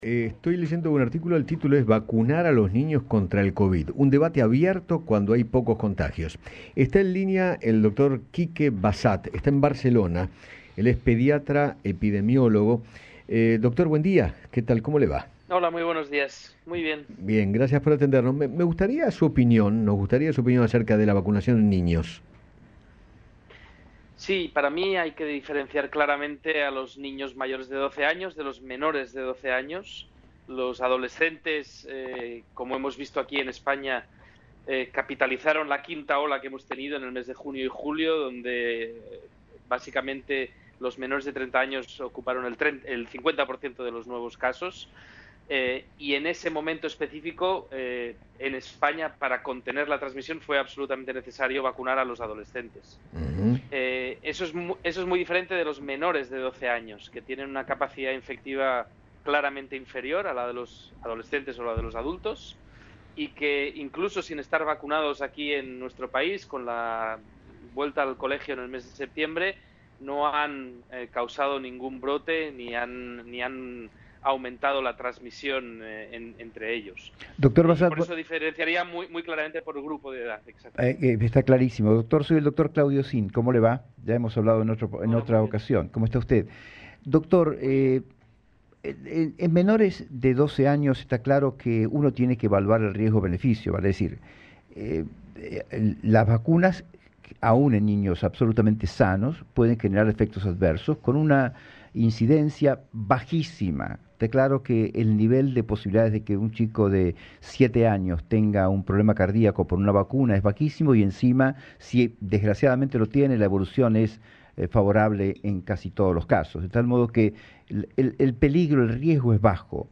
conversó con Eduardo Feinmann sobre la vacunación contra el covid en menores y manifestó que “no han causado ni un brote”.